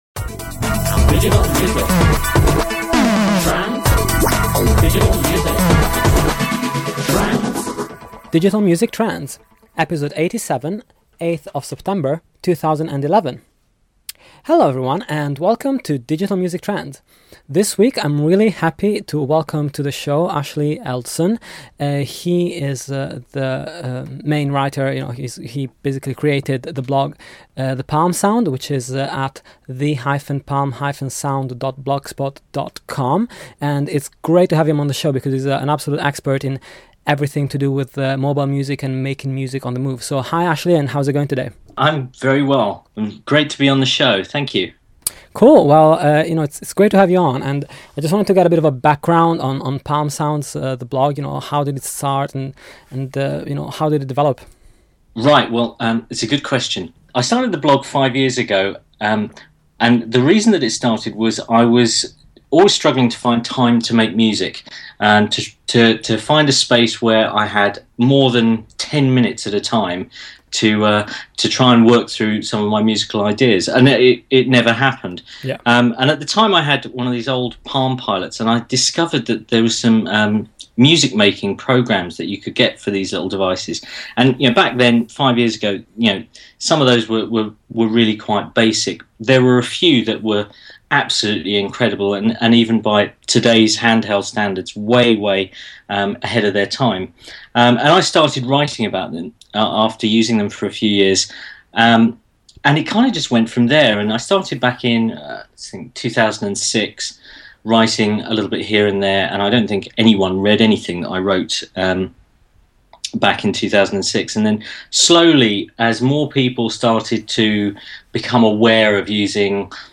This week on the show an interview